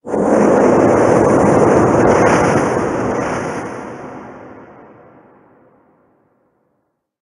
Cri de Pachyradjah Gigamax dans Pokémon HOME.
Cri_0879_Gigamax_HOME.ogg